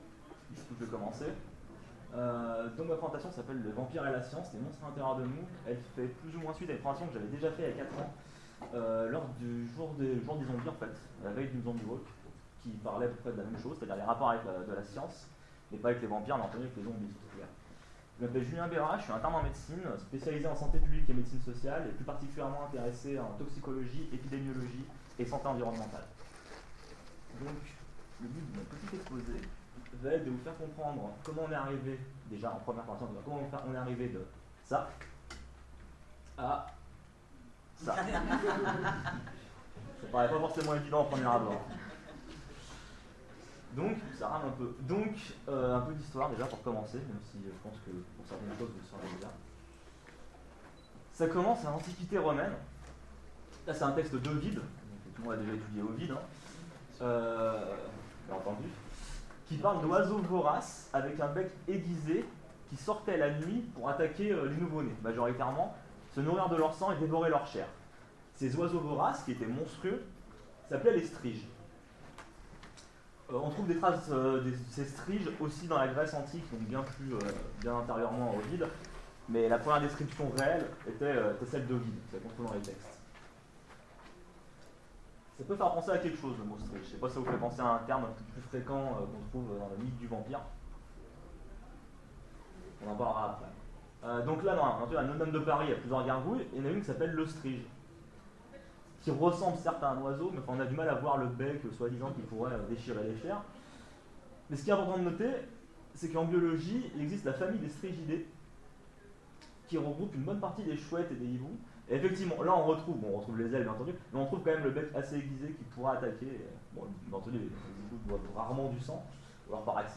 Salon du vampire 2016 : Conférence Vampire et médecine